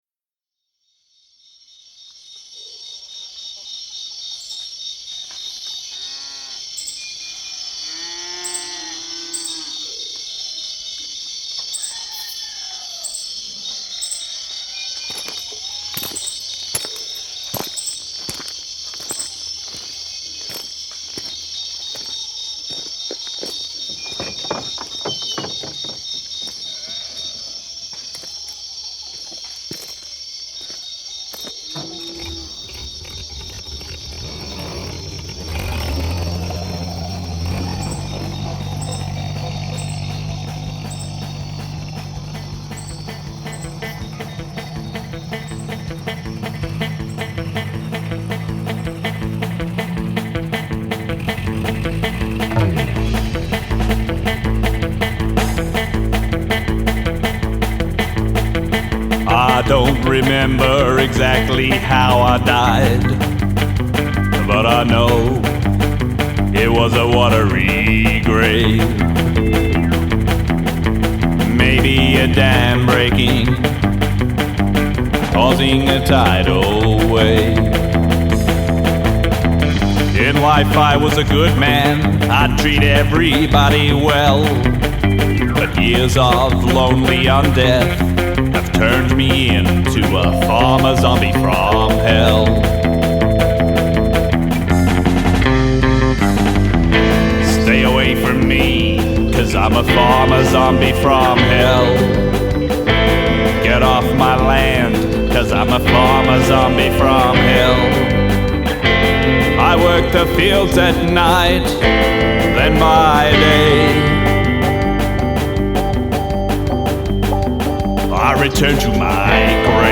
I was experimenting a lot with SFX.
The spooky keyboard